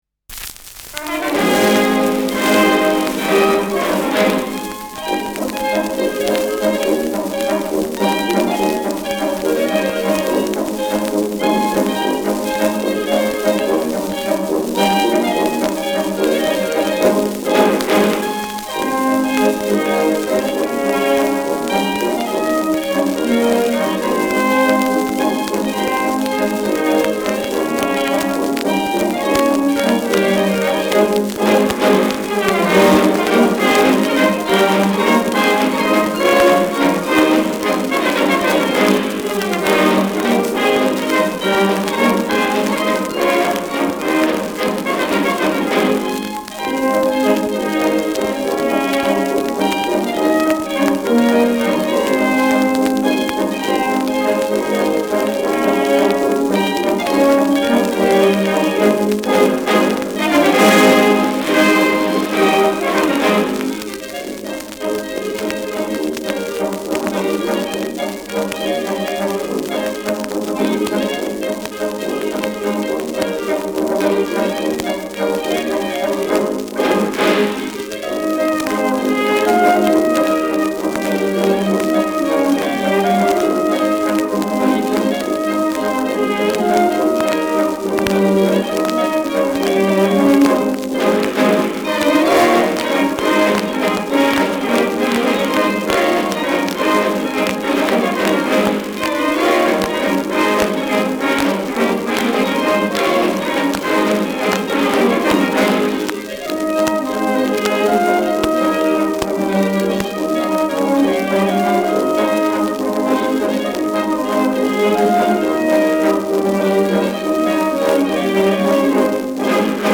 Schellackplatte
präsentes Knistern : präsentes Rauschen : leichtes Leiern : gelegentliches Knacken
Fränkische Bauernkapelle (Interpretation)
[Berlin] (Aufnahmeort)